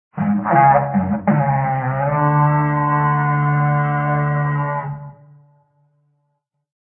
raidhorn_01.ogg